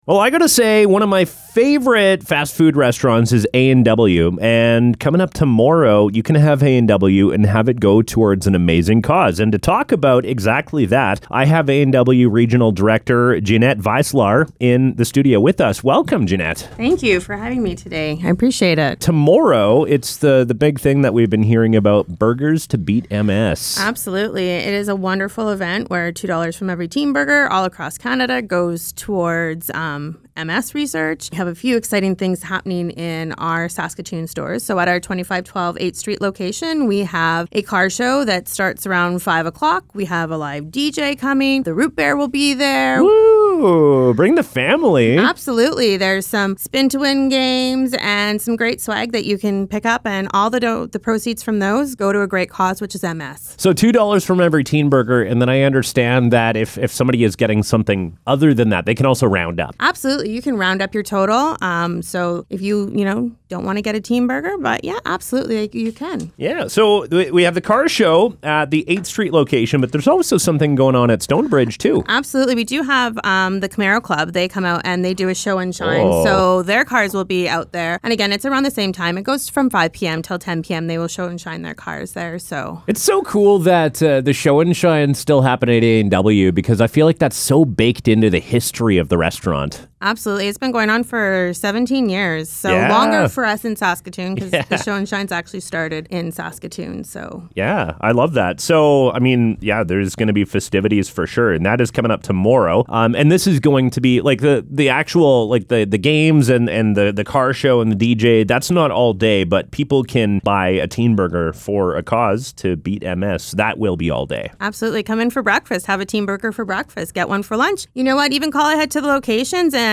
Interview: A&W – Burgers to Beat MS